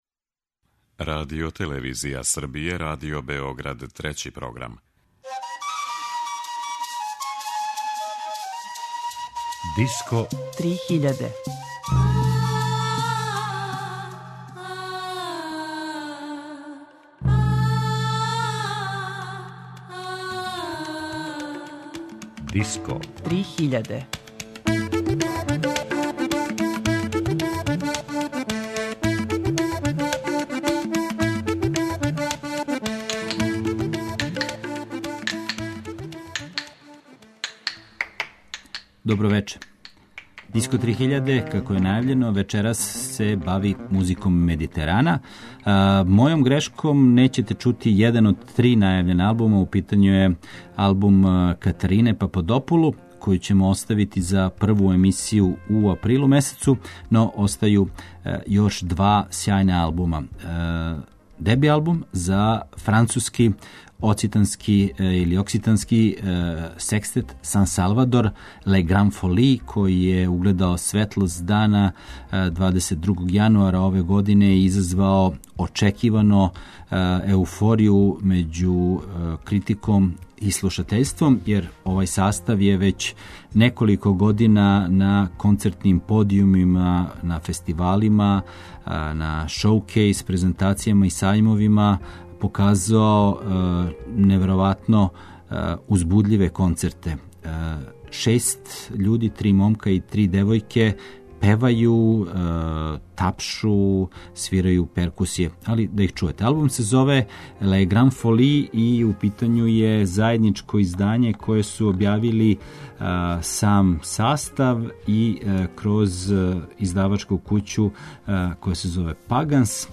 Музика Медитерана
Музика која настаје у земљама Медитерана је разноврсна, богата, узбудљива.